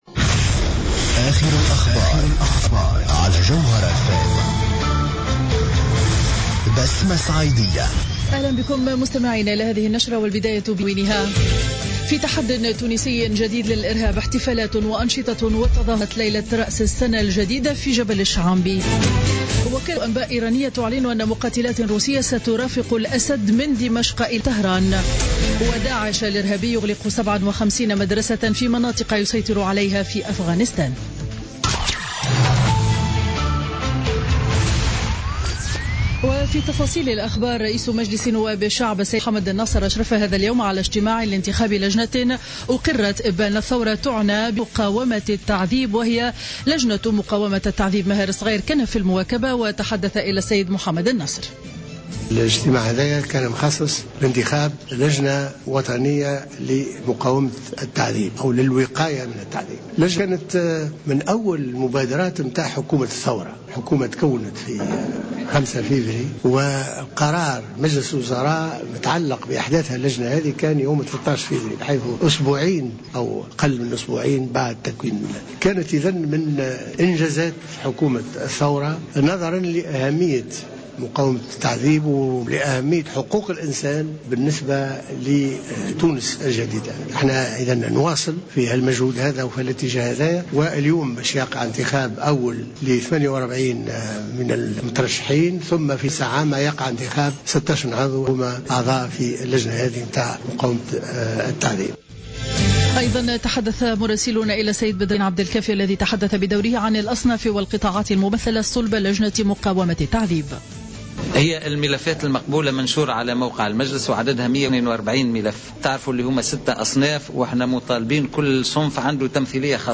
نشرة أخبار منتصف النهار ليوم الإثنين 21 ديسمبر 2015